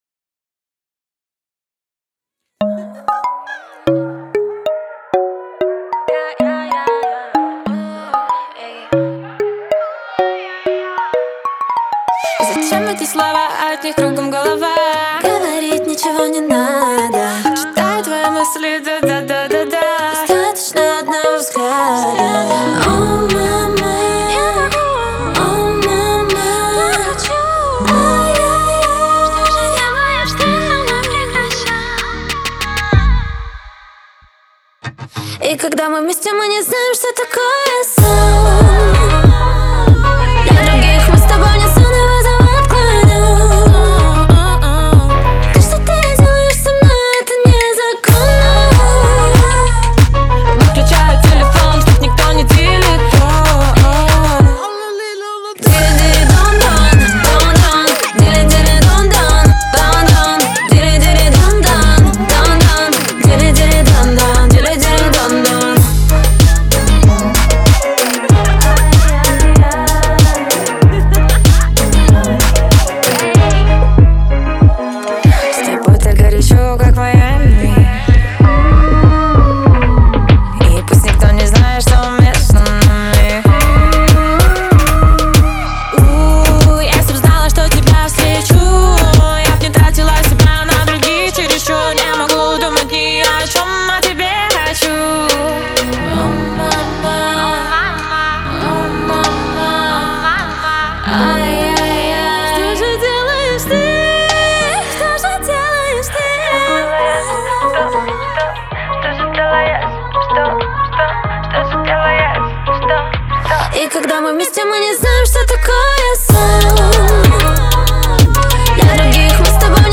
дуэта
это зажигательный трек в жанре поп с элементами фолка